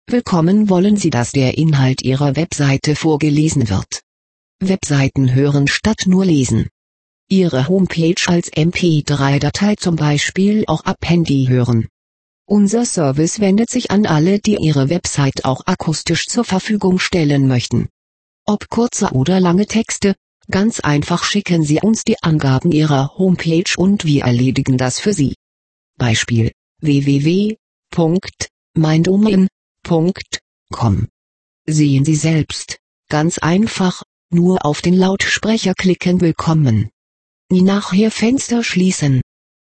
Web Seiten vorlesen lassen